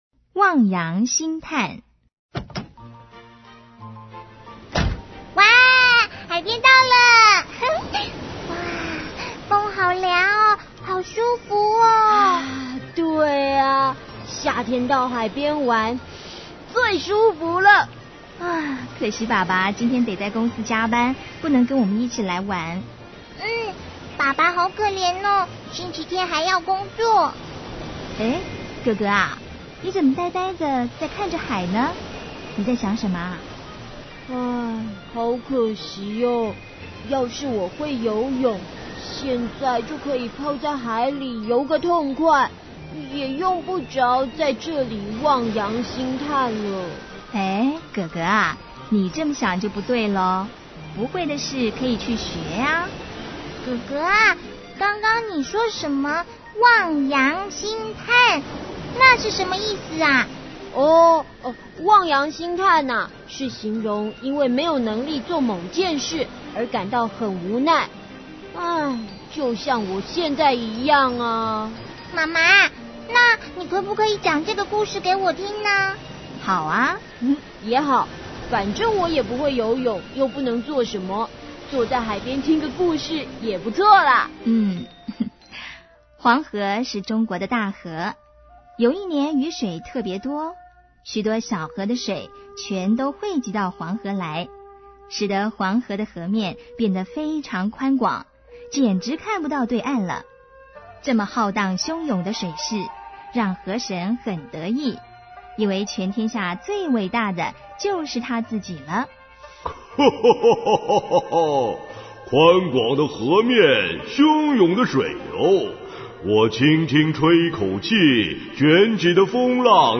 CD 每則成語均錄製成好聽生動的「廣播劇」，增加學習效果。